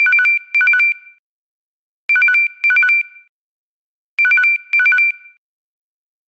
09 Simple Beep.mp3